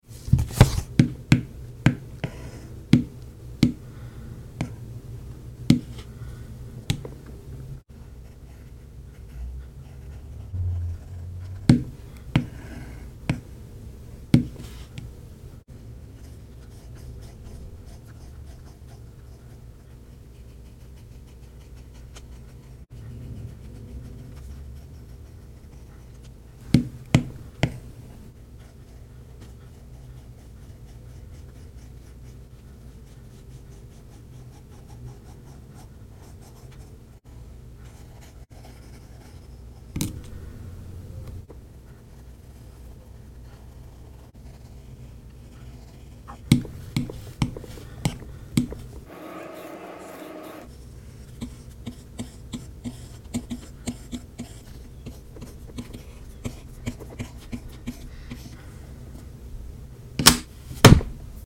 Cozy fall ASMR coloring on sound effects free download
Cozy fall ASMR coloring on my iPad 😊🍂